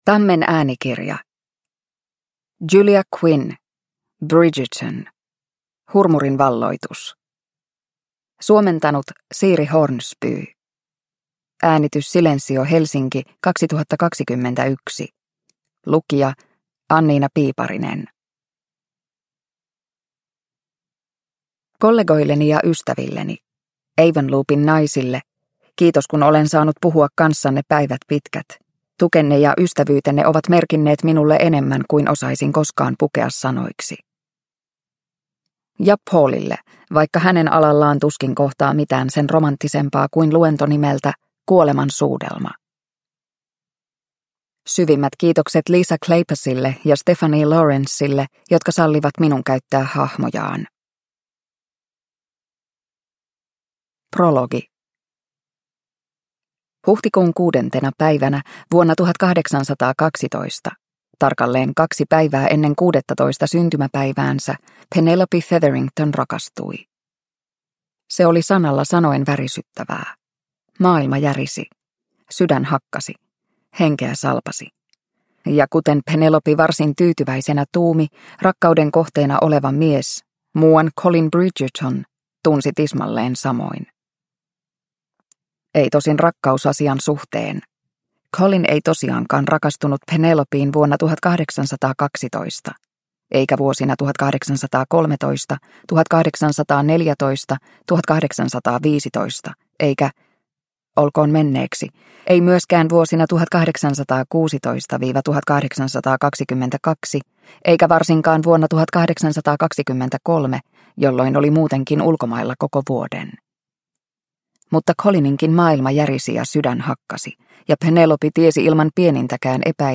Bridgerton: Hurmurin valloitus – Ljudbok – Laddas ner